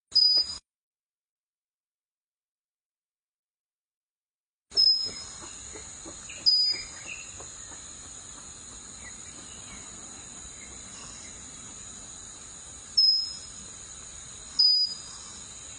小鳞胸鹪鹛的叫声